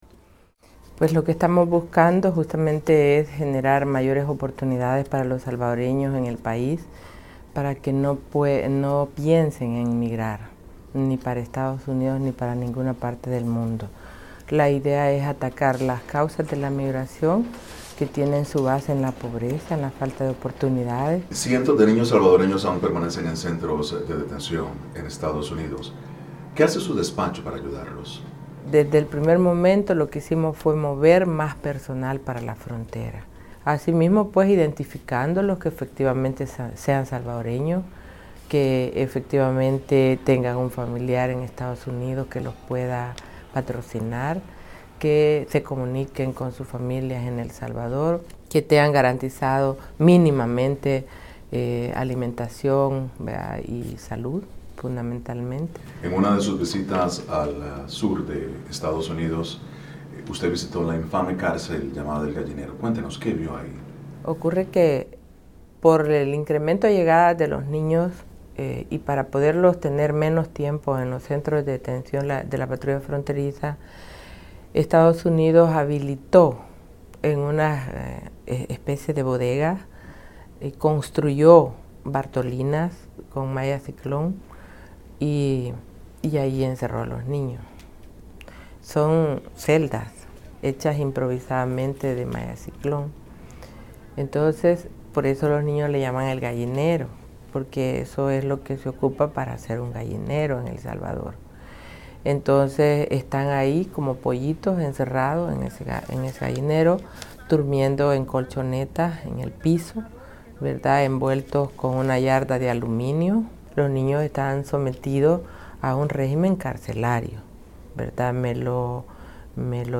Entrevista con la vice canciller de El Salvador, sobre la crisis de los niños migrantes